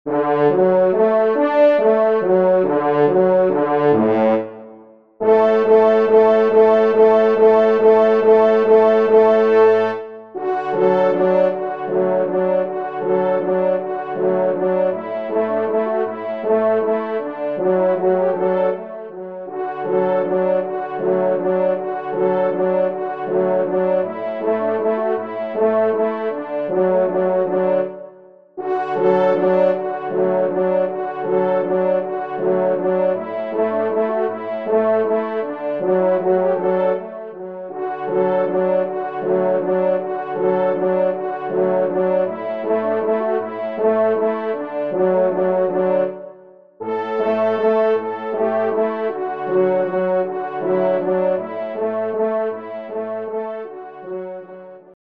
Valse
Genre :  Divertissement pour Trompes ou Cors en Ré (Valse)
4e Trompe